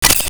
shutter.wav